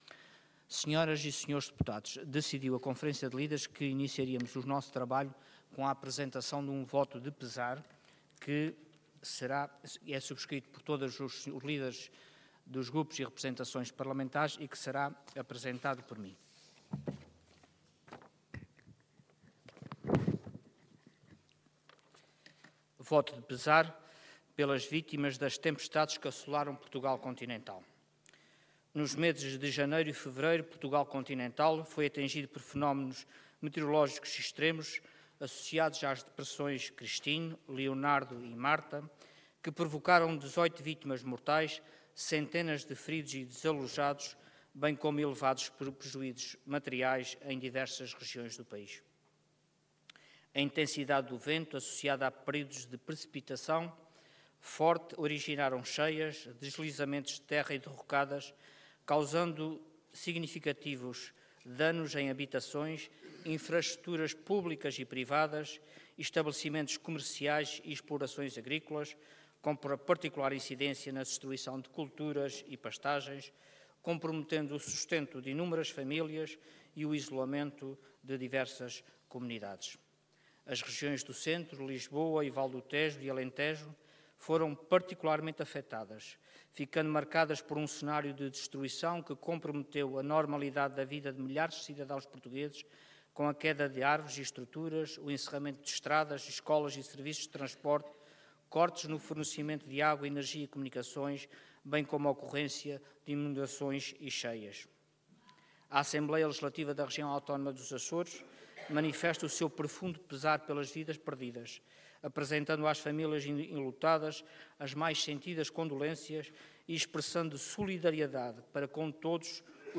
Detalhe de vídeo 24 de fevereiro de 2026 Download áudio Download vídeo Processo XIII Legislatura Voto de Pesar pelas vítimas das tempestades que assolaram Portugal Continental Intervenção Voto de Pesar Orador Luís Garcia Cargo Presidente da Assembleia Regional Entidade ALRAA